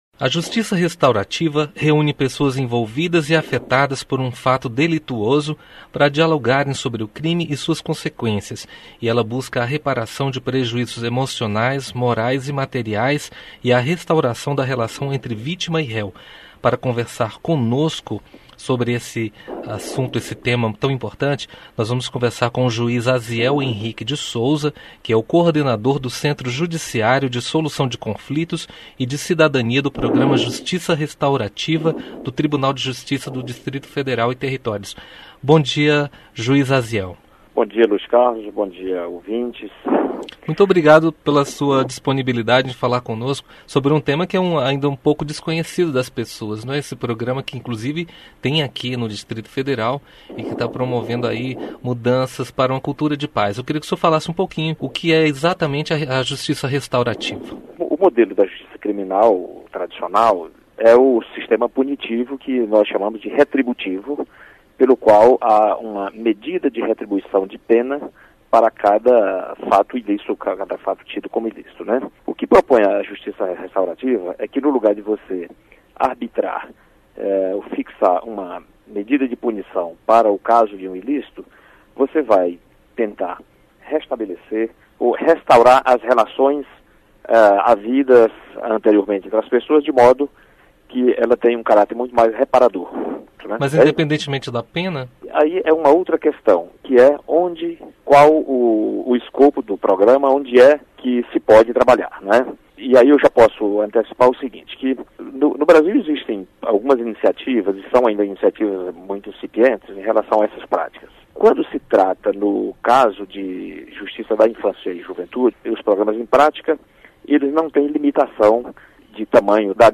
Entrevista com o juiz Aziel Henrique de Souza, do programa Justiça Restaurativa do Tribunal de Justiça do Distrito Federal e Territórios.